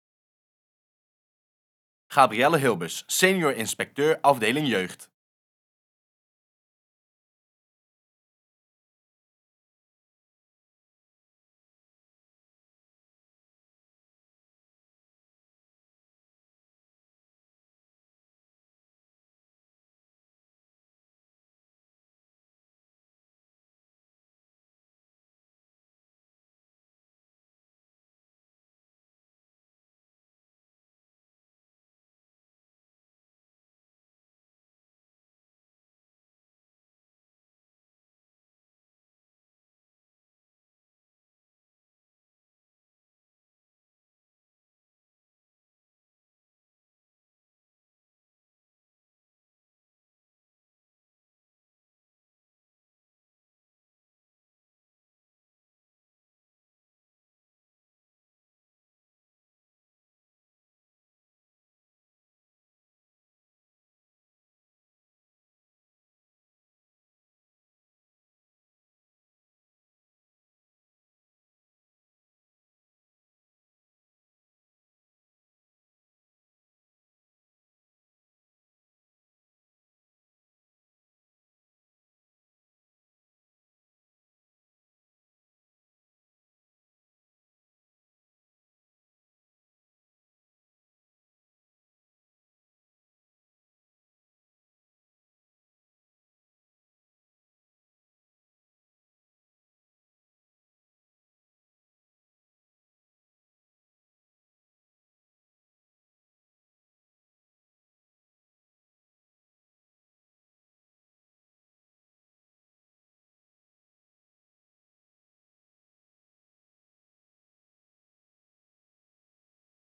Ontdek wat jouw toekomstige collega’s van hun werk vinden